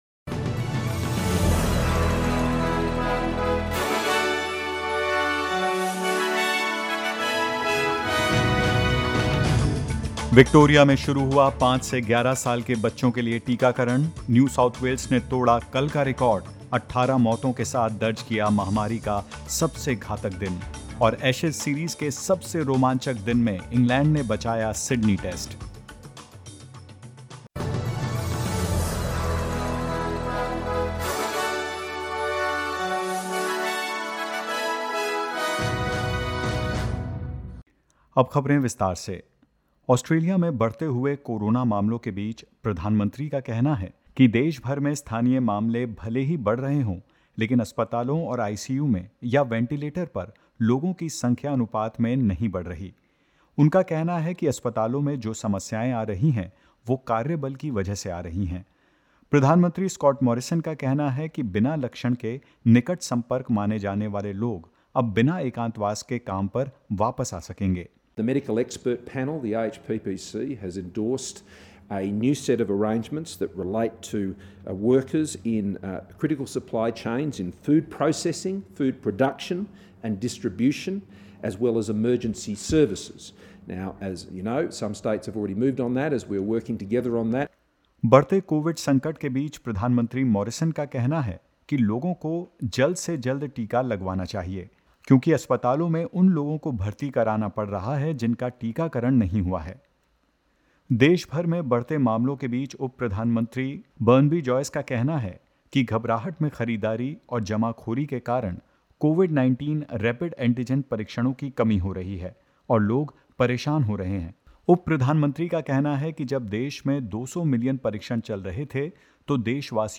In this latest SBS Hindi news bulletin: Deputy Prime Minister Barnaby Joyce says panic buying and hoarding is leading to shortages of COVID-19 rapid antigen tests; Children aged 5 to 11 are finally eligible to get their first dose of the Pfizer COVID vaccine today and more.